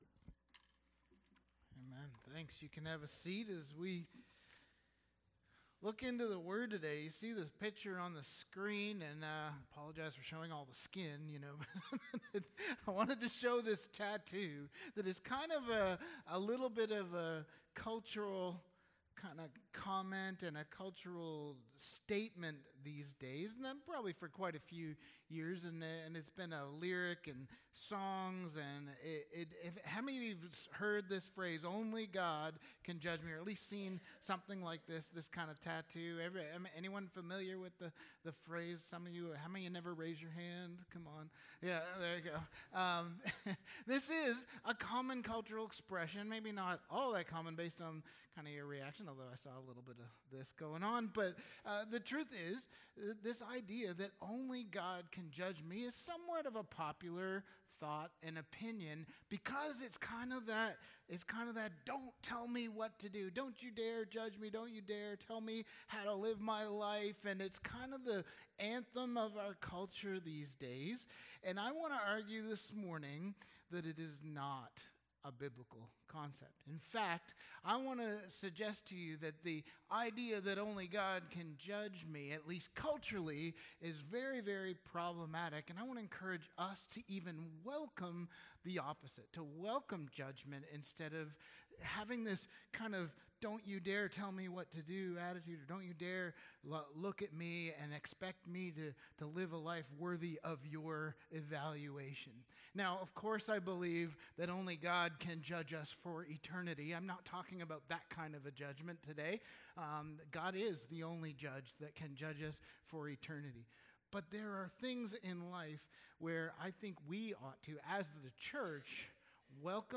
Sermons | Sunnyside Wesleyan Church